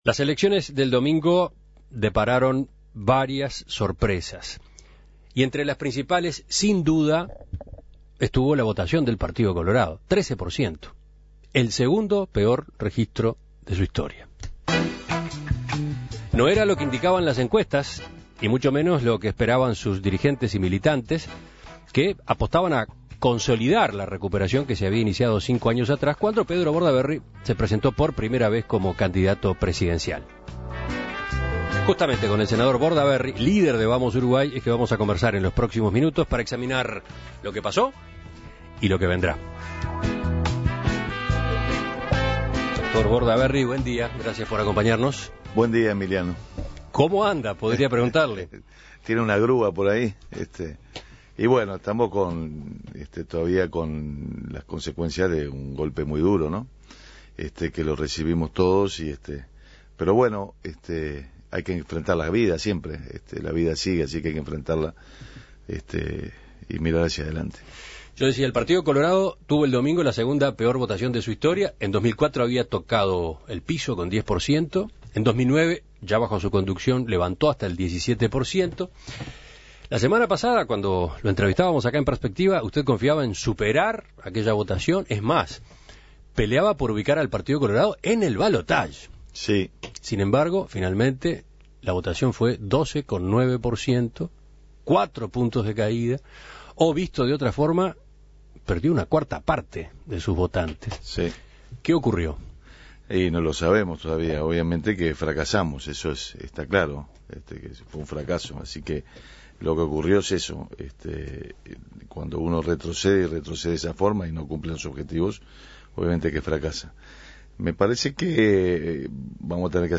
Estas y otras preguntas estuvieron en la entrevista en En Perspectiva, en la que asumió su responsabilidad por los resultados del domingo.